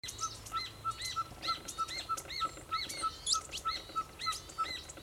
Ferruginous Pygmy Owl (Glaucidium brasilianum)
Life Stage: Adult
Condition: Wild
Certainty: Recorded vocal